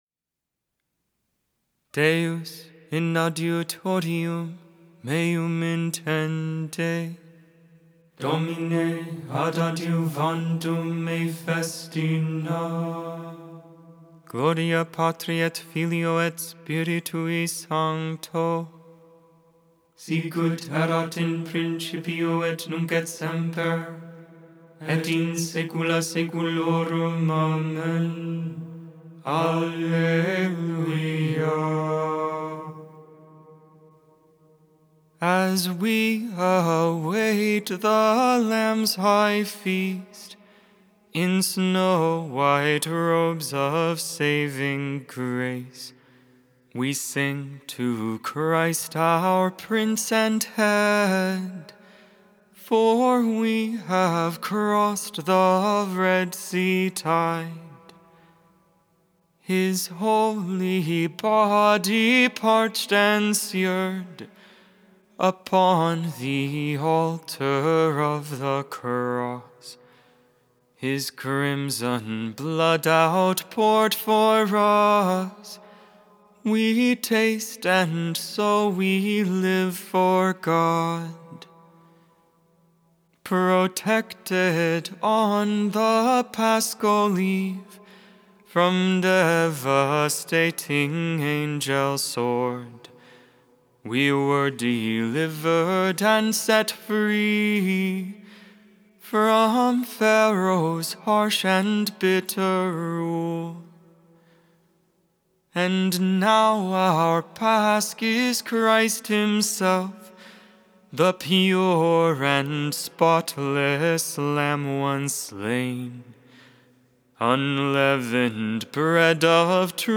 Music, Christianity, Religion & Spirituality